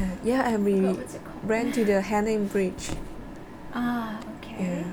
S1 = Brunei female S2 = Chinese female Context: S2 is talking about a trip she took to Temburong, a district of Brunei famous for its scenery.
S2: yeah Intended Word: hanging Heard as: haling Discussion: The medial consonant in hanging sounds like [l].